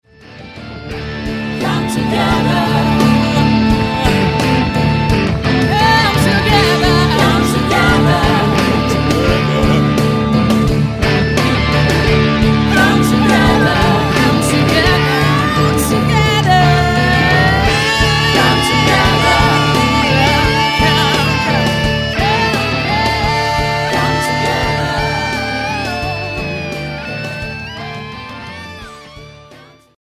Microfono registrazione voci AKG C 414 XLII